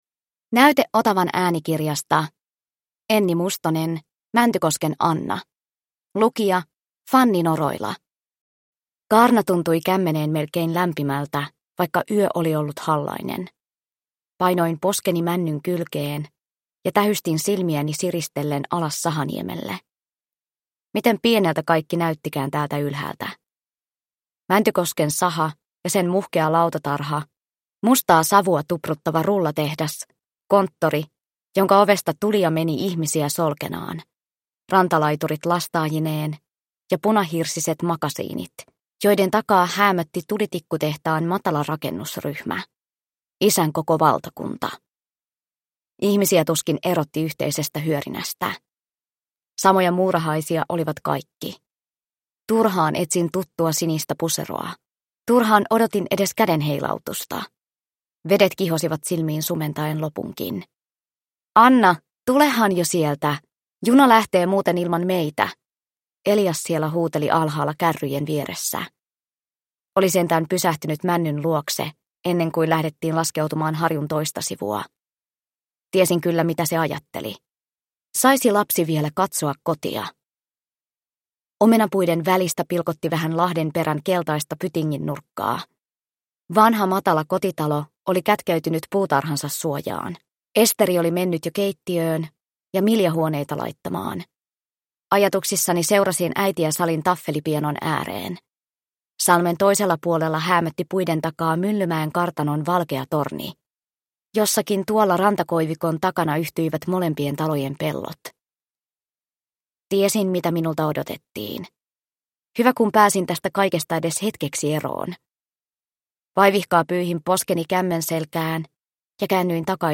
Mäntykosken Anna – Ljudbok – Laddas ner